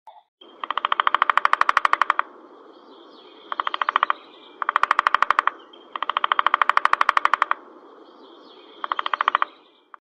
دانلود صدای دارکوب از ساعد نیوز با لینک مستقیم و کیفیت بالا
جلوه های صوتی
برچسب ها: دانلود آهنگ های افکت صوتی انسان و موجودات زنده دانلود آلبوم صدای دارکوب نوک زدن از افکت صوتی انسان و موجودات زنده